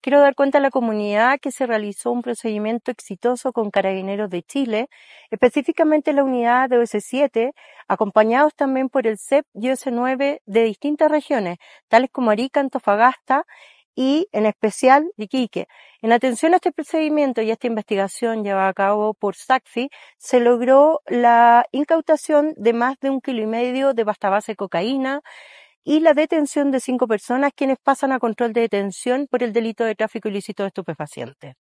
La Fiscal Regional Trinidad Steinert explicó que la investigación permitió confirmar que distintos sujetos vendían droga en casas ocupadas ilegalmente, generando temor entre los vecinos.